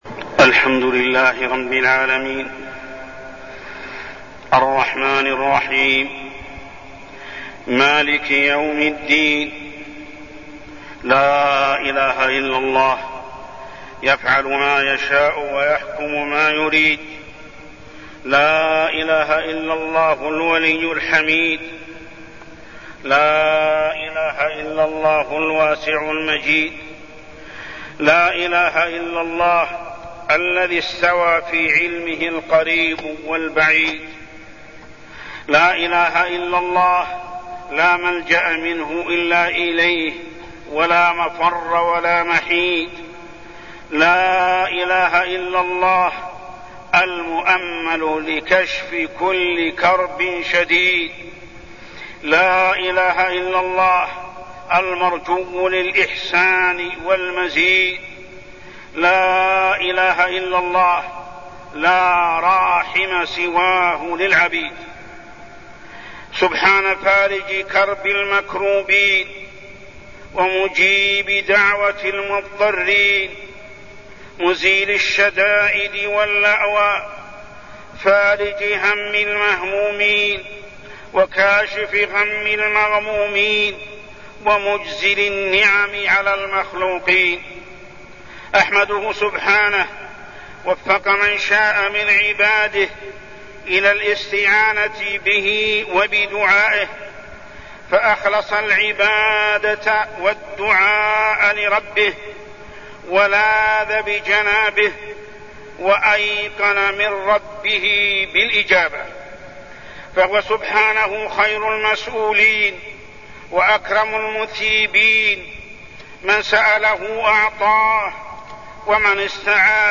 تاريخ النشر ٢٦ جمادى الآخرة ١٤١٦ هـ المكان: المسجد الحرام الشيخ: محمد بن عبد الله السبيل محمد بن عبد الله السبيل إقبال الأنبياء على الله